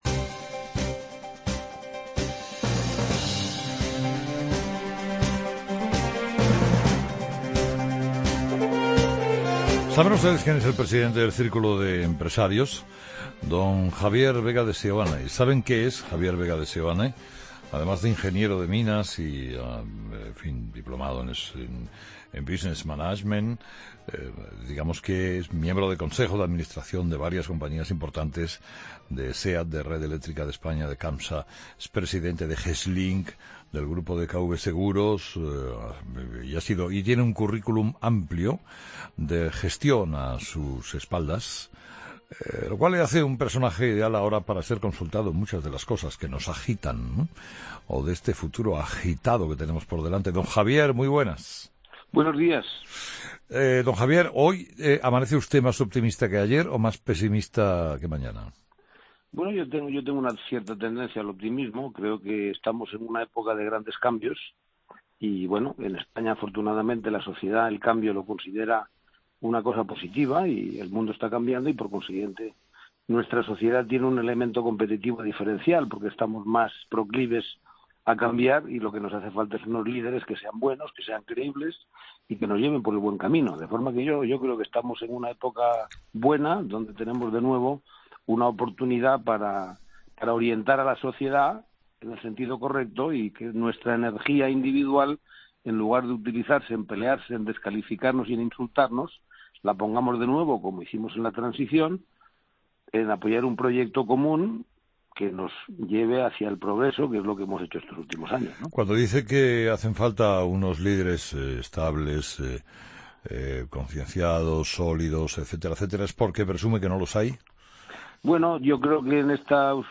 Escucha la entrevista a Javier Vega de Seoane, presidente del Círculo de Empresarios, en 'Herrera en COPE'